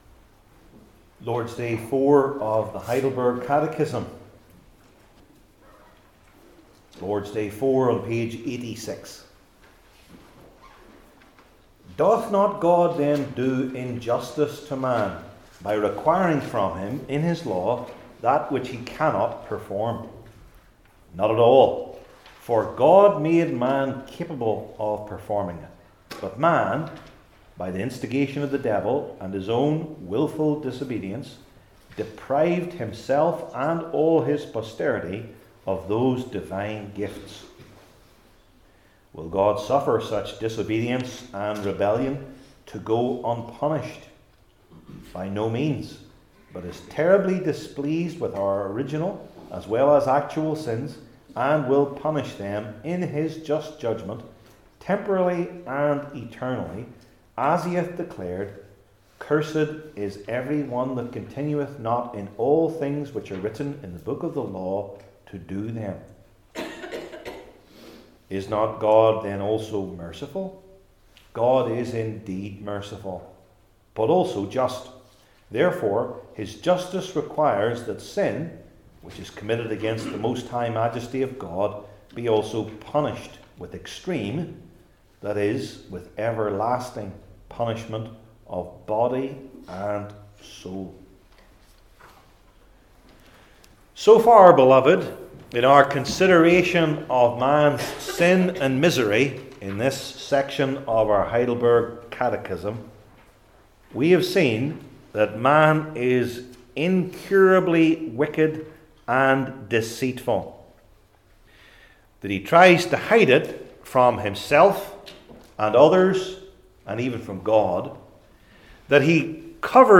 Deuteronomy 32:1-25 Service Type: Heidelberg Catechism Sermons I. God’s Just Requirement II.